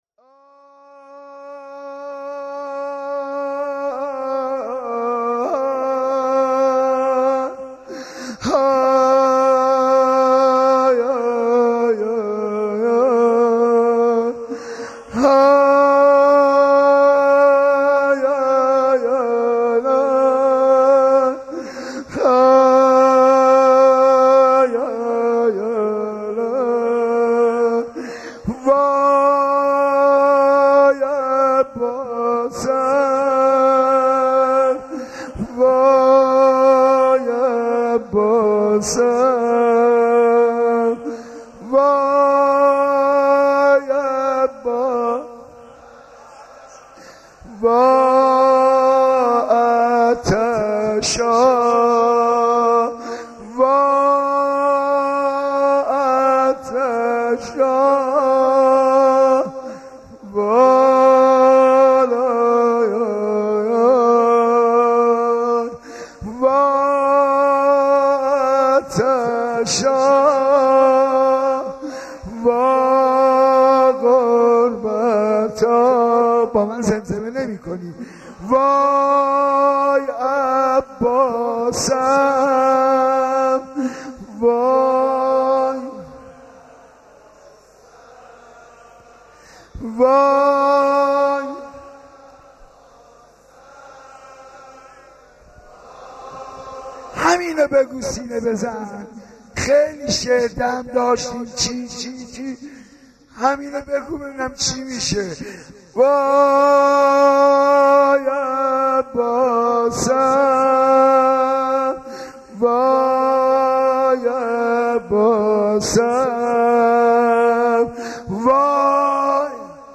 مداح
مناسبت : شب دوم محرم
قالب : روضه